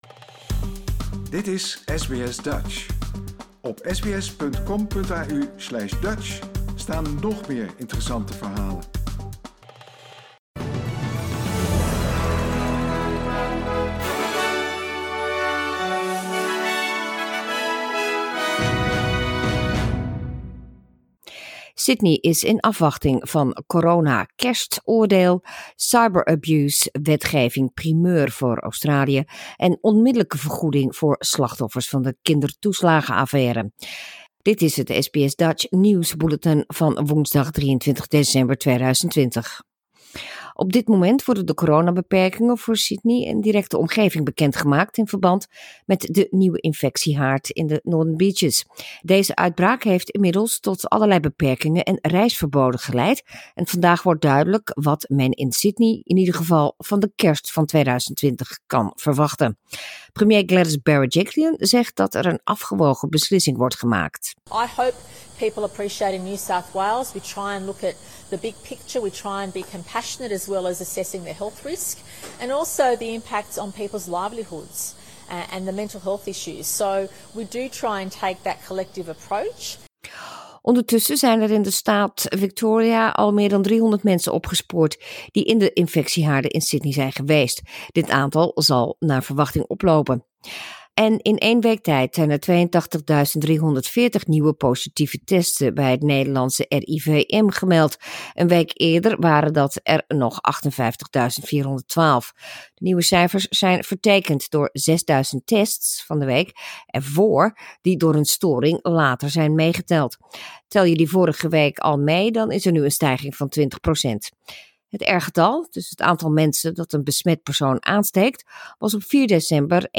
Nederlands/Australisch SBS Dutch nieuwsbulletin woensdag 23 december 2020